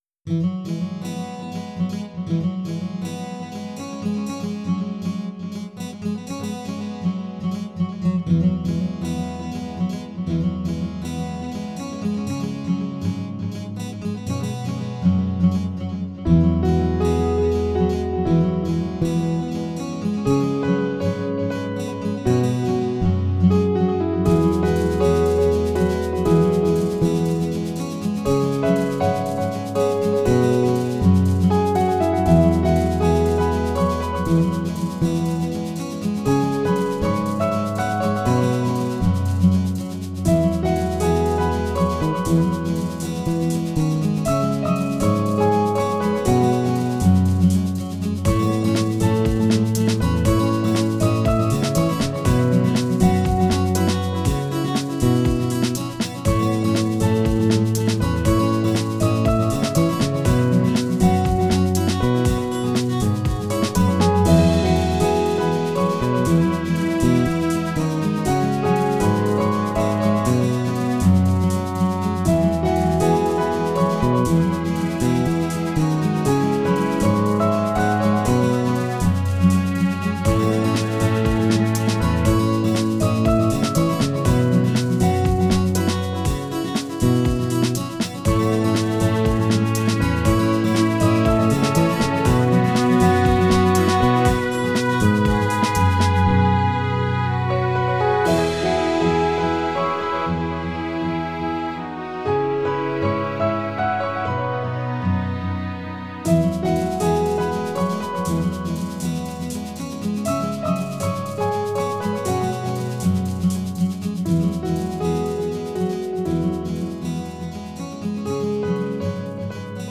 Tags: Piano, Strings, Guitar, Percussion